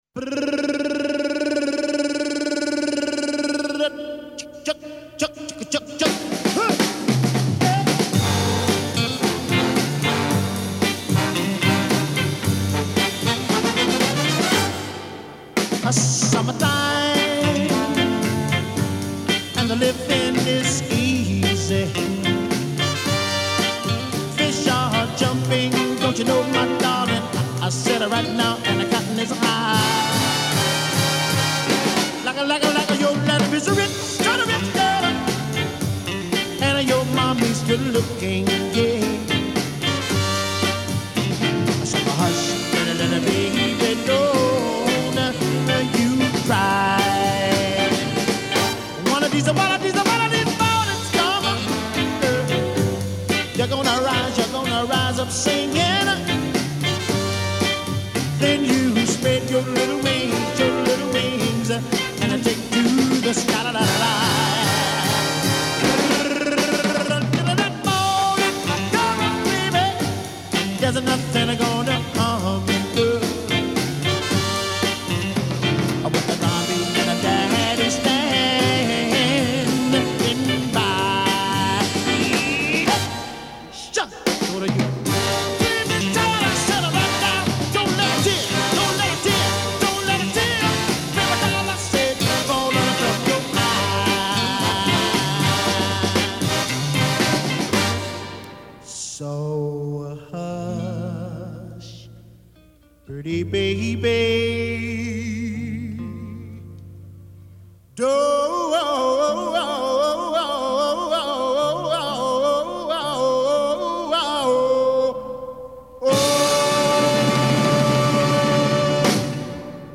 TEMPO : 112